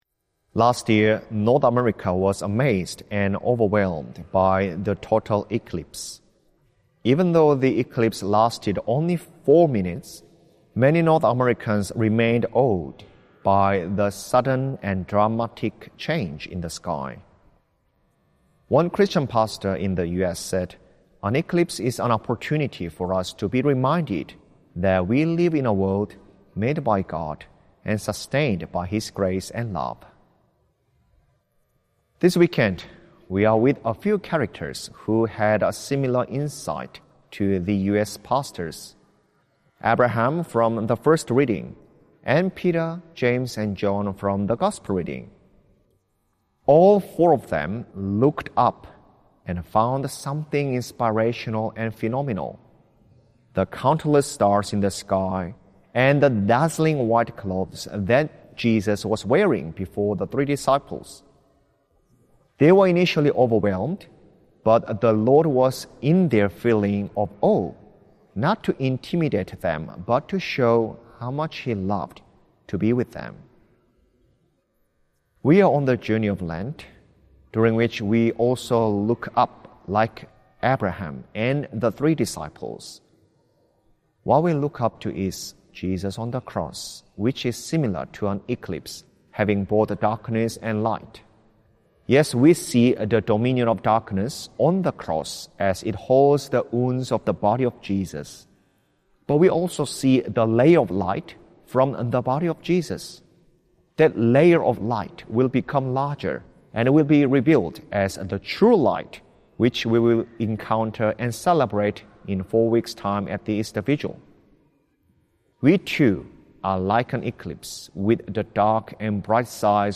Archdiocese of Brisbane Second Sunday of Lent - Two-Minute Homily